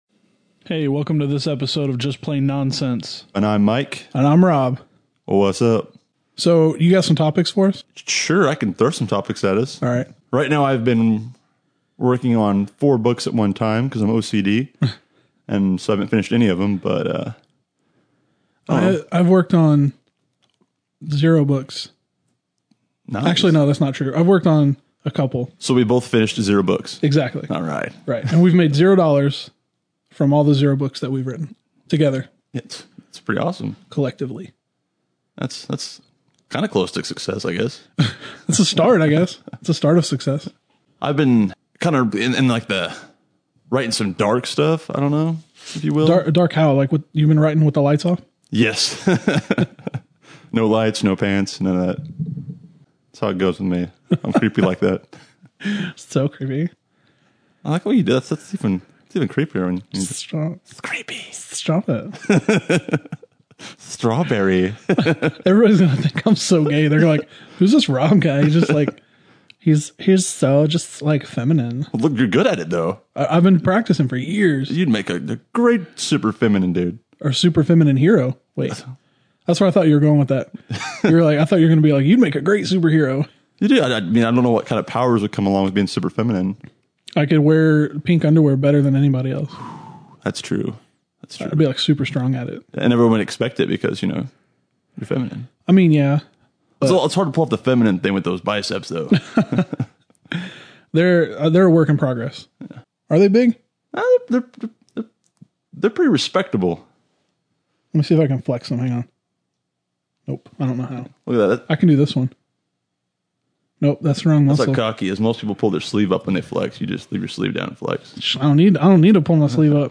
they attempt some pretty terrible impressions